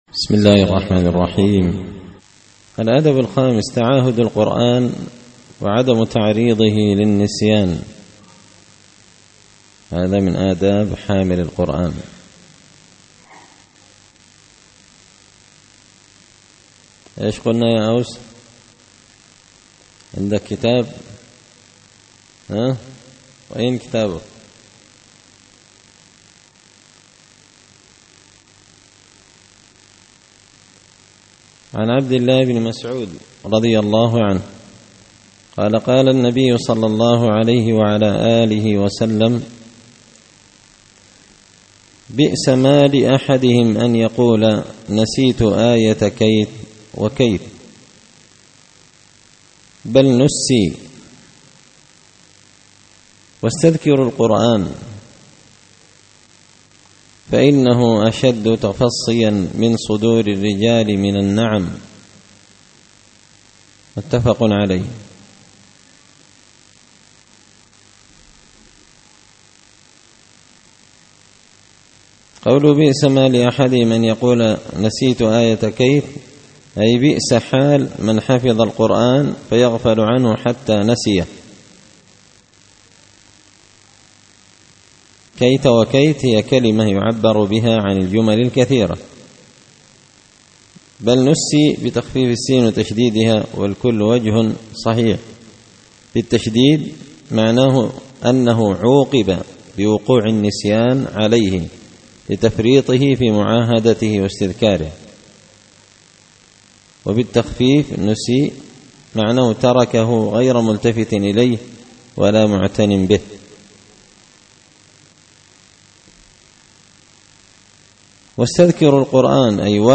الدرس
دار الحديث بمسجد الفرقان ـ قشن ـ المهرة ـ اليمن